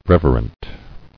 [rev·er·ent]